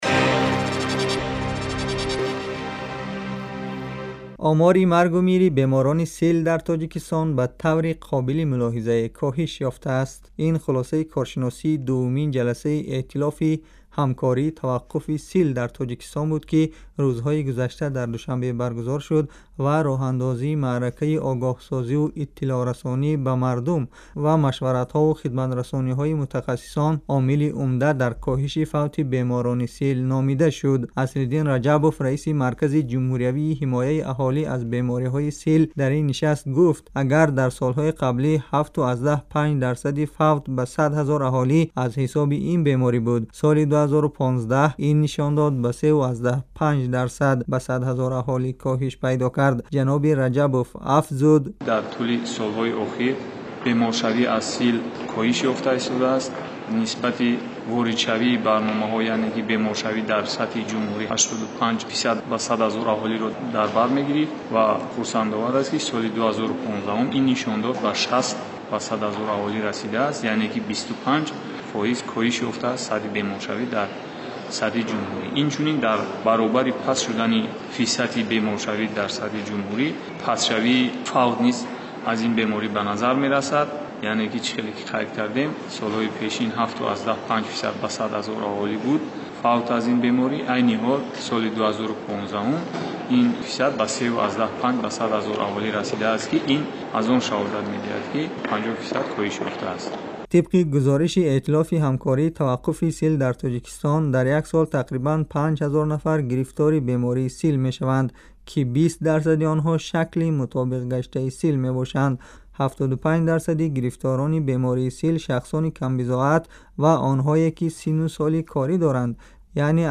гузориши вижа